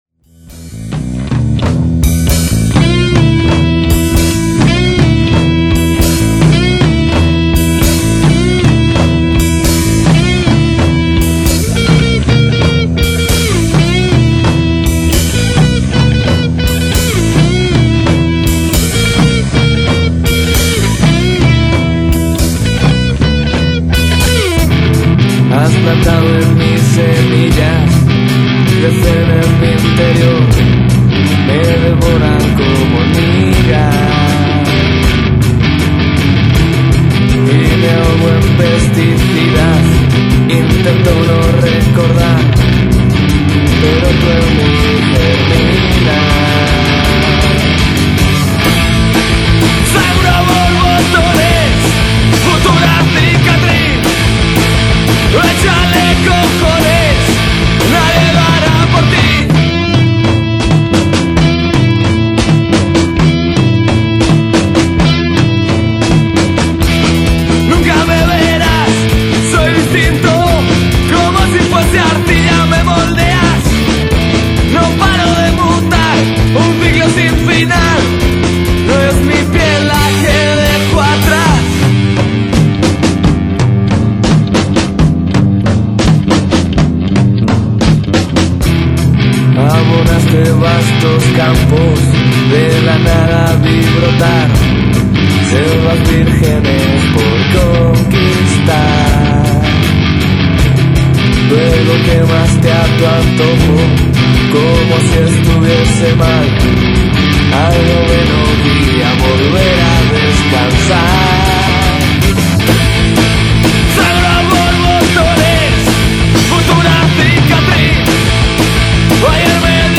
Entrevista a Fridonia
Entrevista_a_Fridonia.mp3